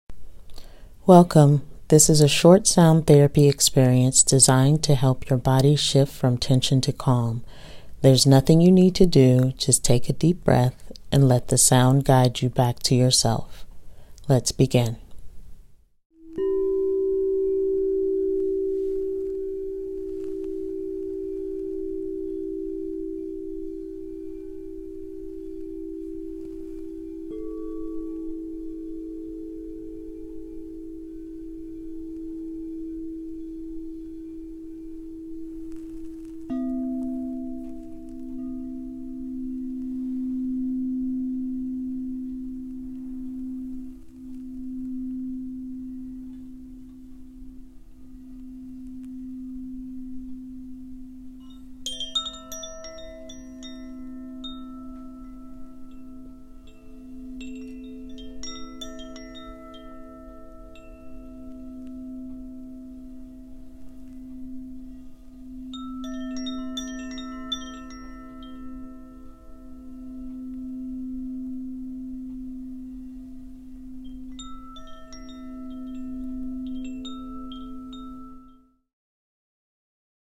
Through the intentional use of instruments like crystal bowls, tuning forks, chimes, and Tibetan singing bowls, I guide your body into a state of deep rest and resonance.
New to sound healing?